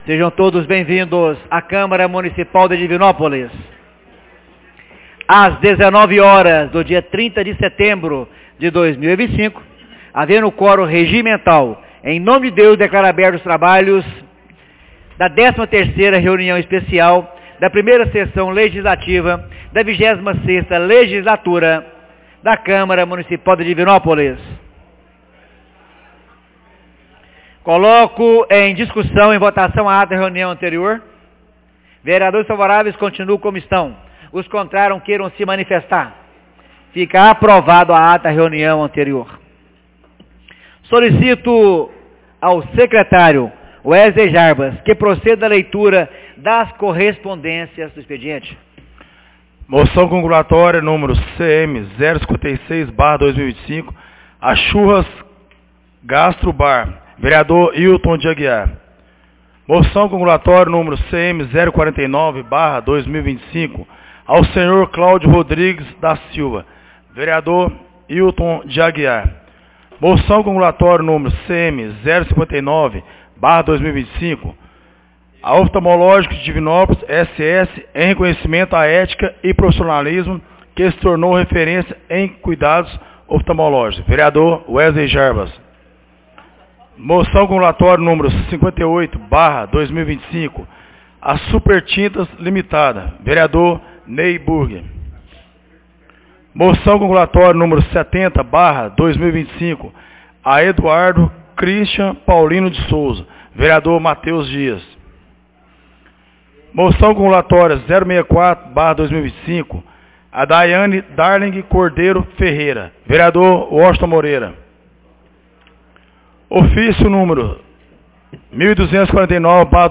Reuniões Especiais